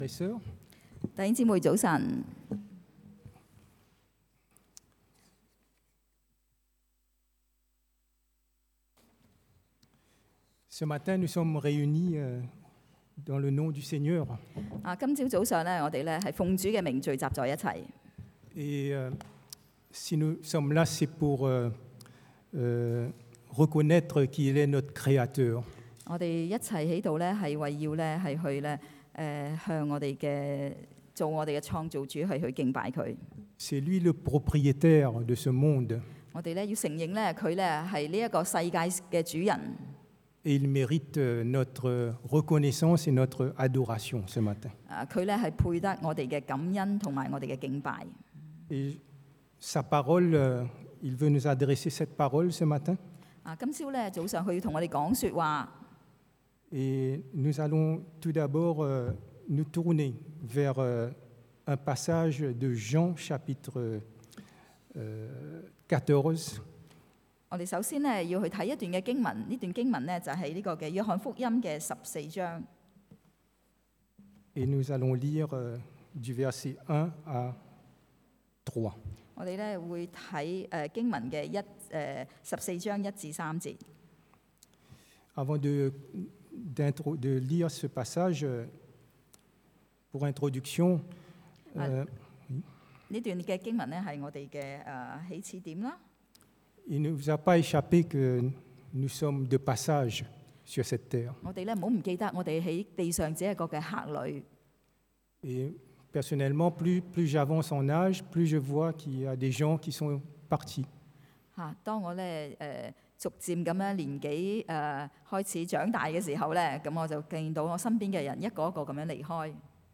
Citoyen du ciel 天上的国民 – Culte du dimanche